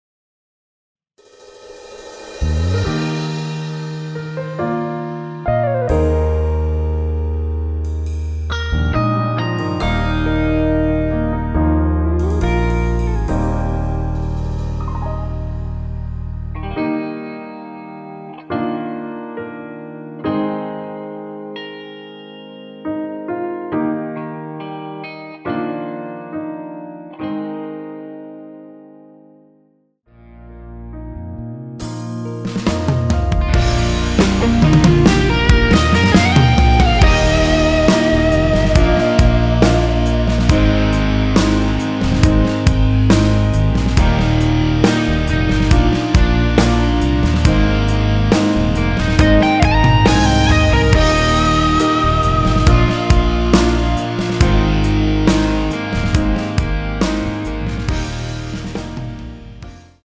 원키에서(-1)내린 MR입니다.
앞부분30초, 뒷부분30초씩 편집해서 올려 드리고 있습니다.
중간에 음이 끈어지고 다시 나오는 이유는
곡명 옆 (-1)은 반음 내림, (+1)은 반음 올림 입니다.